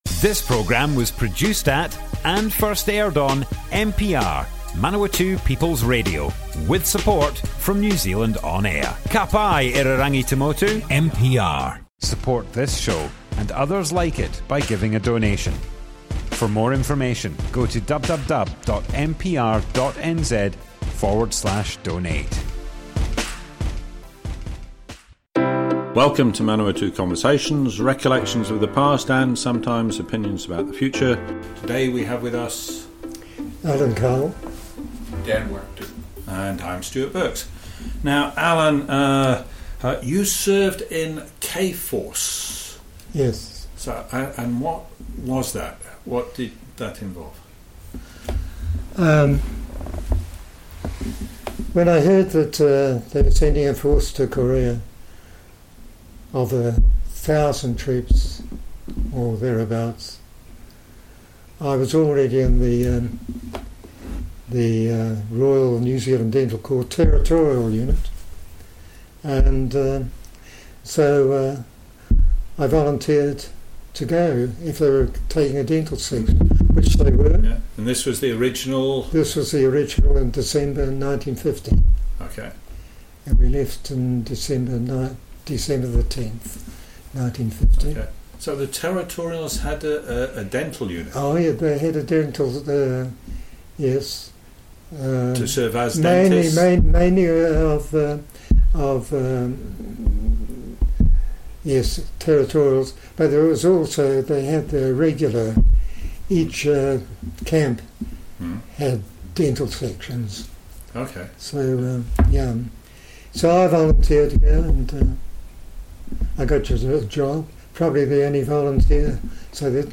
Manawatu Conversations Object type Audio More Info → Description Broadcast on Manawatu People's Radio, 2nd February 2021.
oral history